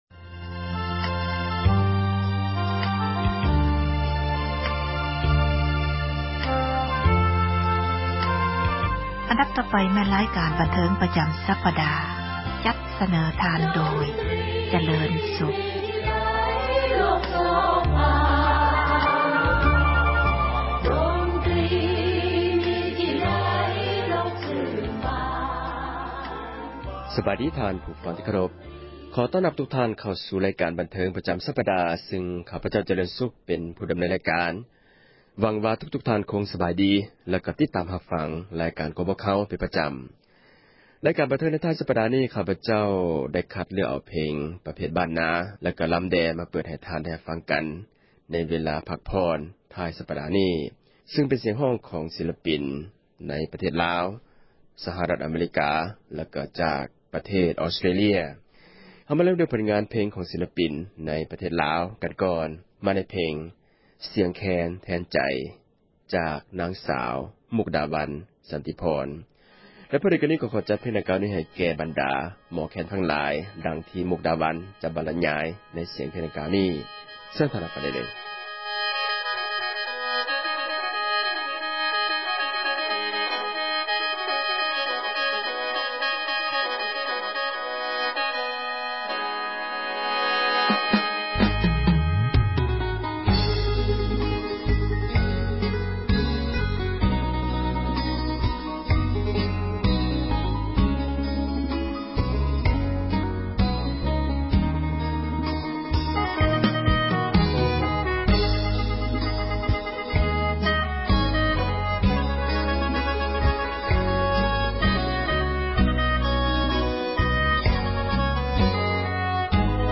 ຣາຍການ ດົນຕຣີ ເພງລາວ ສມັຍ-ສາກົນ ນໍາສເນີ ທ່ານຜູ້ຟັງ ໃຫ້ຮູ້ຈັກ ນັກຮ້ອງ ນັກແຕ່ງ ເພງລາວ ທັງ ອະດີດ ແລະ ປະຈຸບັນ ໃນທົ່ວ ທຸກມຸມໂລກ.